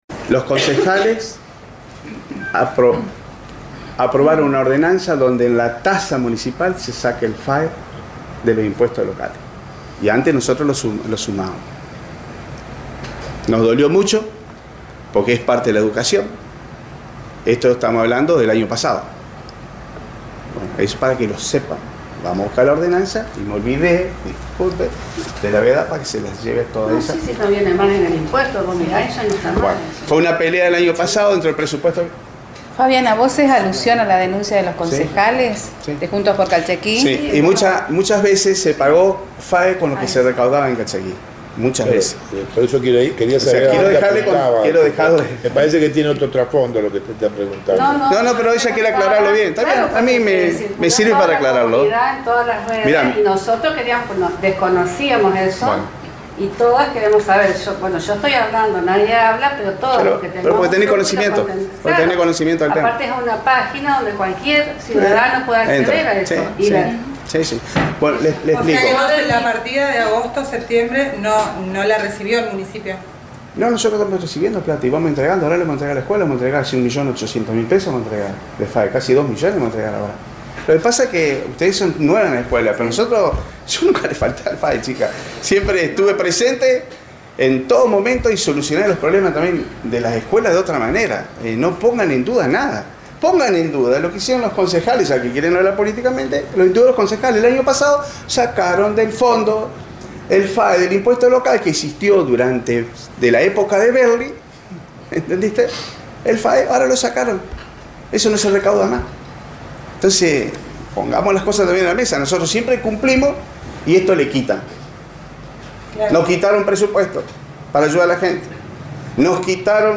Enfatizó el intendente ante los docentes rurales, al plantear su posición sobre la denuncia de la oposición. Además sostuvo que la provincia nunca hizo efectivo el aporte para sostener el traslado de docentes secundarios a los centros rurales.